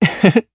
Zafir-Vox_Happy1_jp.wav